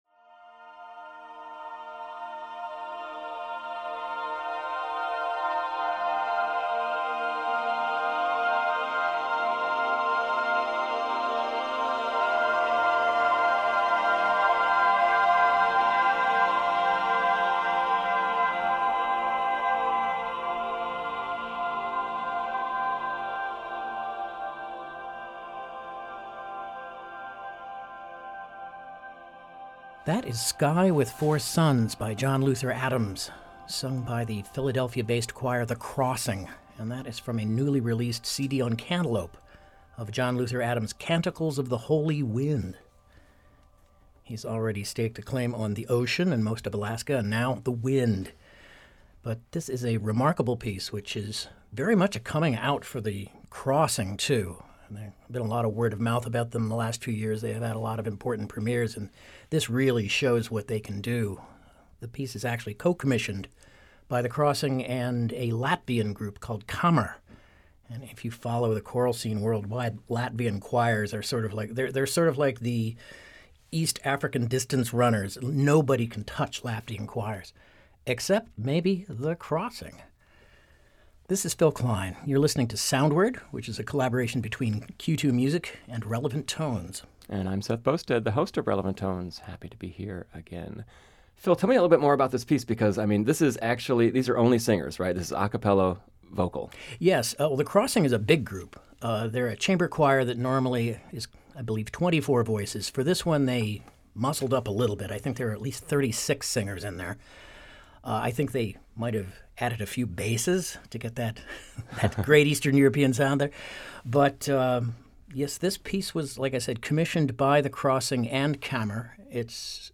It’s a format as old as time: two guys sit down to play music and talk about it.